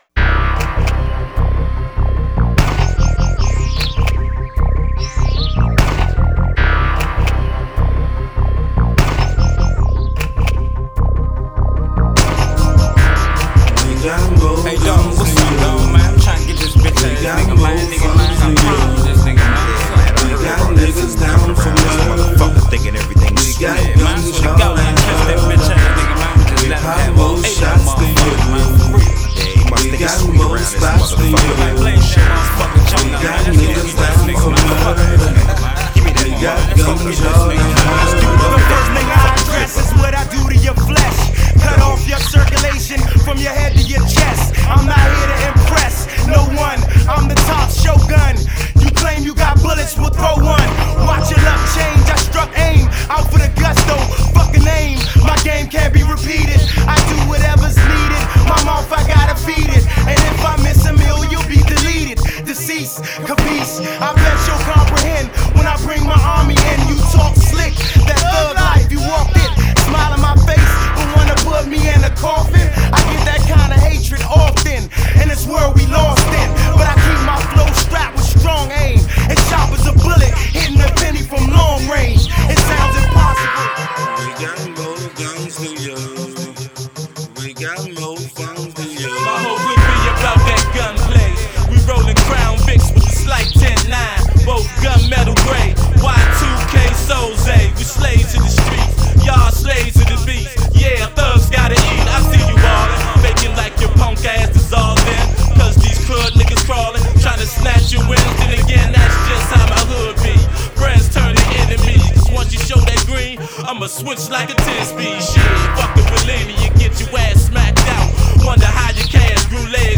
Hier heute 2000 D.C. Sound